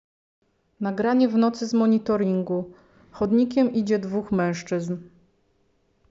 Opis nagrania: audiodeskrypcja